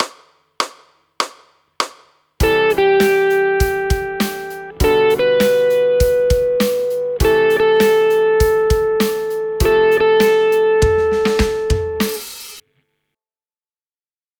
We have chosen the I-V-vi-IV progression, which is one of the most popular chord progressions of all time.
The next set of examples plays the scale tone followed by the release, in this case the closest chord tone.
Sixth Resolving to Chord Tone (ex. b)